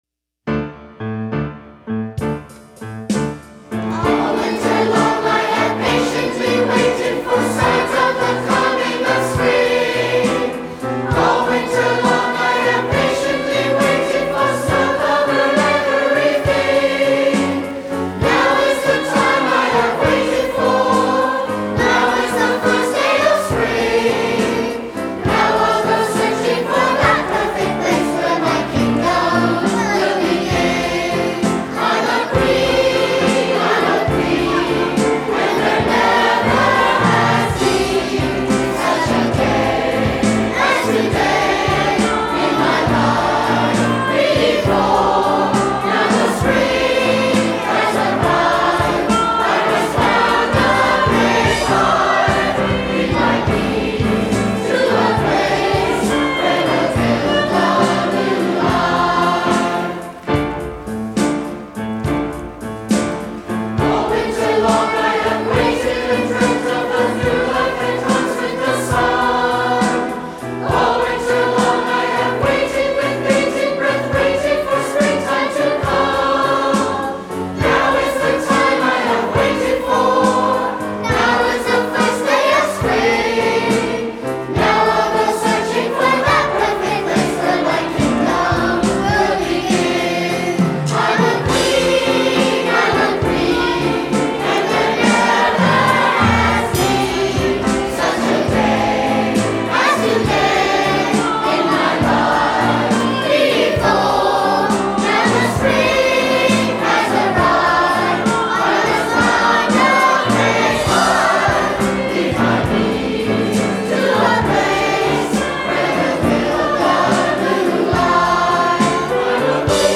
Below you can hear the 2007 Festival Chorus performing Lifetime: Songs of Life and Evolution.
Performed with students from the Baldwin School.